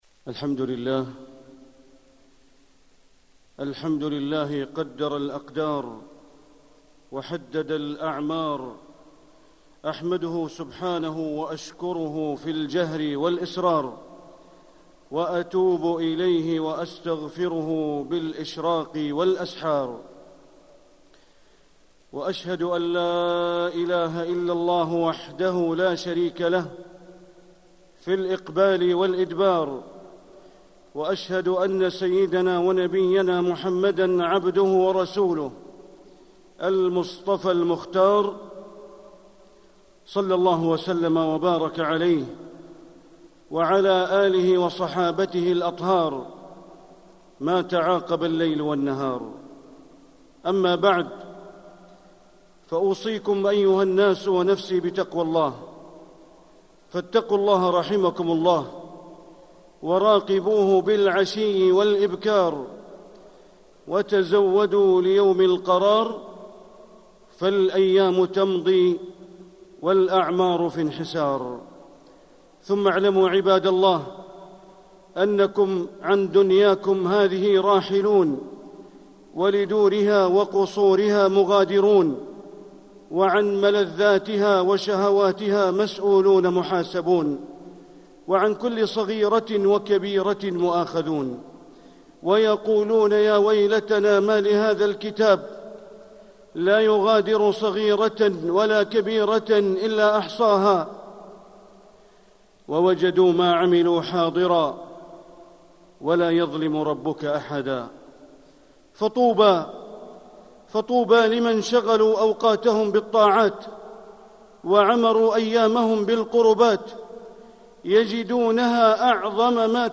مكة: الانتفاع بالعمر للآخرة - بندر بن عبد العزيز بليلة - طريق الإسلام
مكة: الانتفاع بالعمر للآخرة - بندر بن عبد العزيز بليلة (صوت - جودة عالية